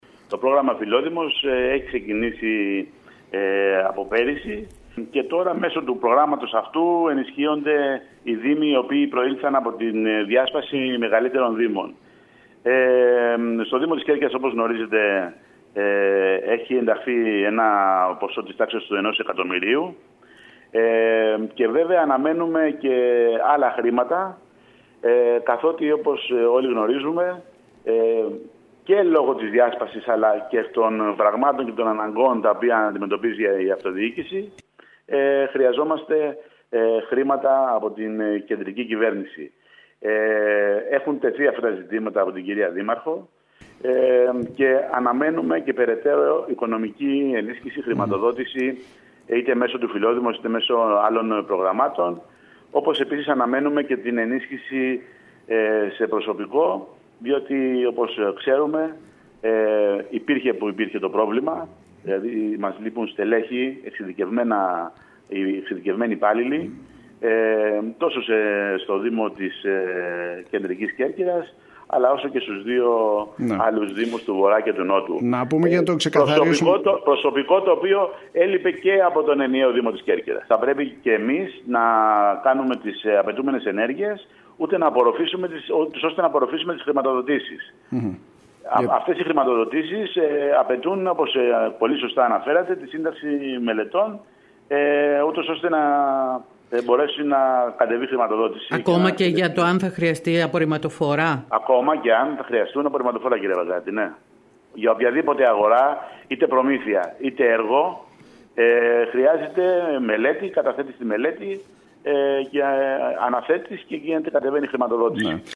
Για το θέμα μίλησε στο σταθμό μας ο Δημοτικός Σύμβουλος Γιώργος Παντελιός ο οποίος έχει την προσωρινή ευθύνη διαχείρισης των οικονομικών του Δήμου Κεντρικής Κέρκυρας.